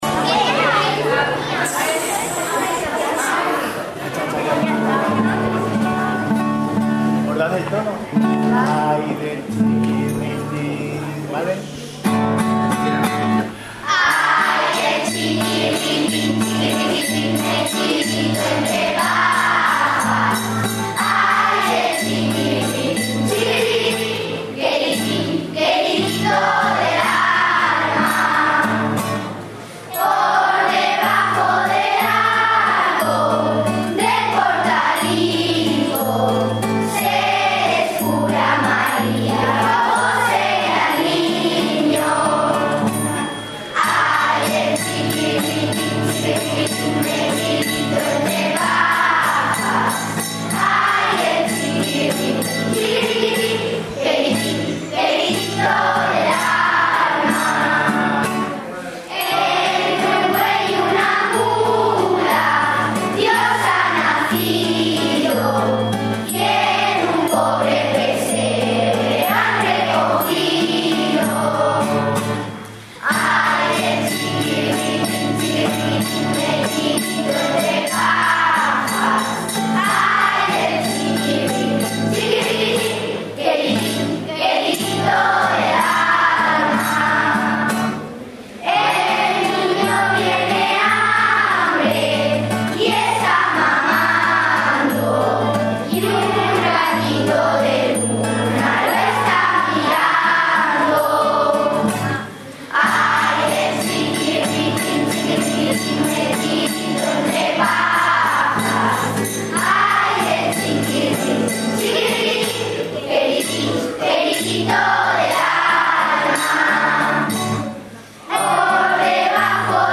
El acto de inauguración ha finalizado con la interpretación de los niños y niñas de ambos colegios de un villancico.
Villancico-Colegio-Las-Delicias.mp3